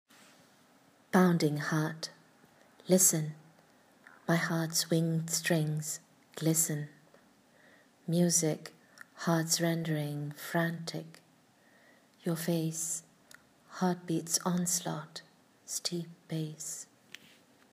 Reading of the poem